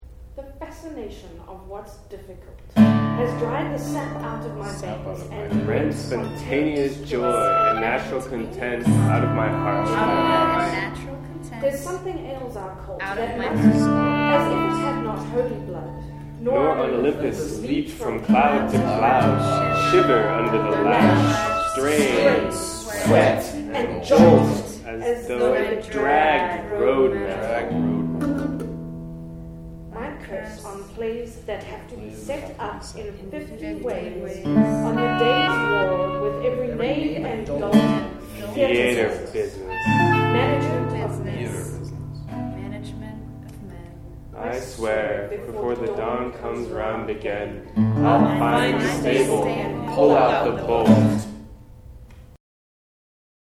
All sound recorded by Parallel Octave on Sunday, September 9, 2012, in the Arellano Theater on the JHU campus.